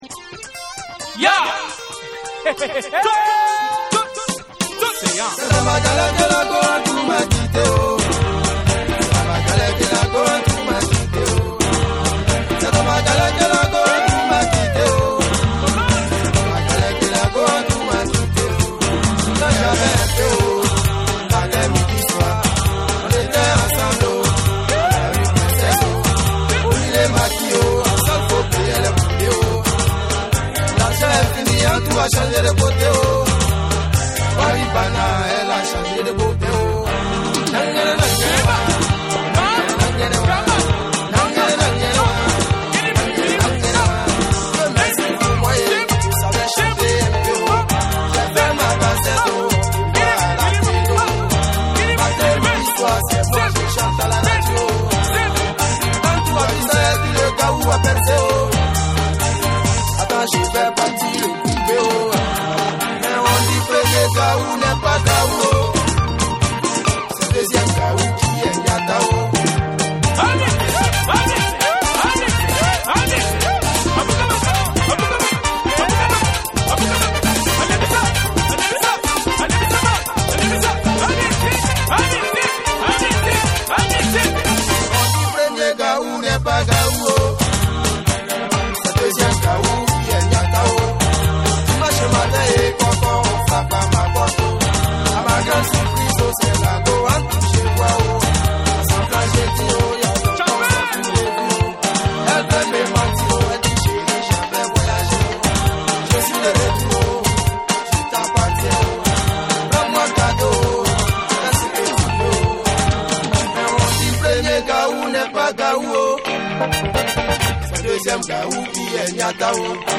NEW RELEASE / ORGANIC GROOVE